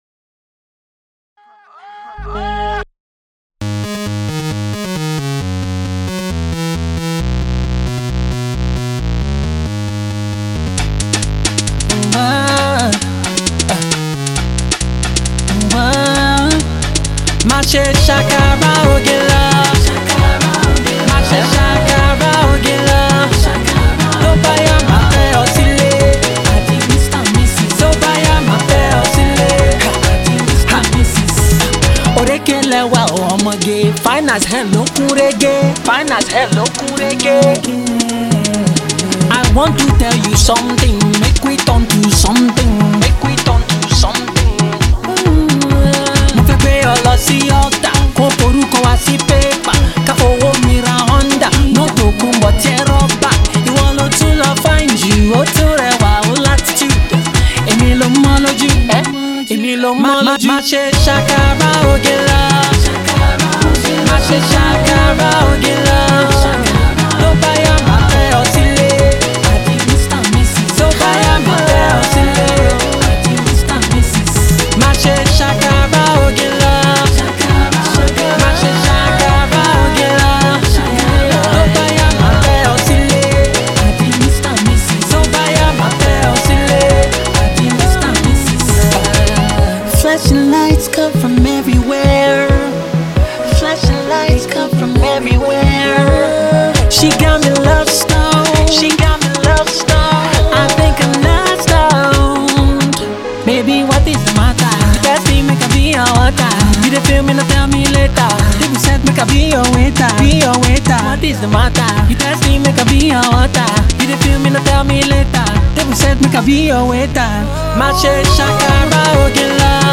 the more ethnic storytelling side of his artistry